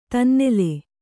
♪ tannele